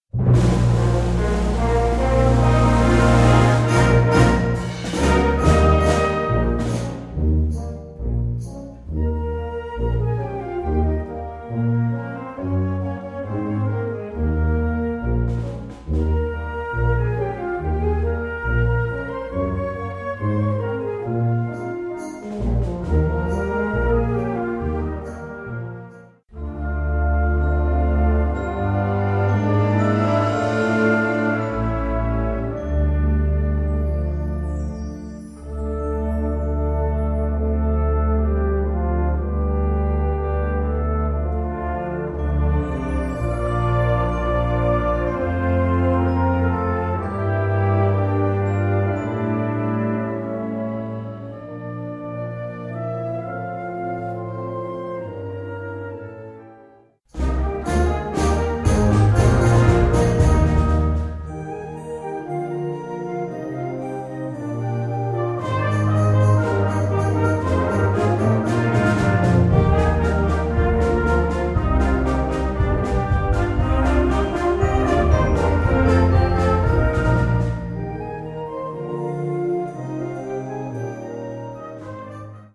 Subcategorie Concertmuziek
Bezetting Ha (harmonieorkest); / (oder); Fa (fanfare)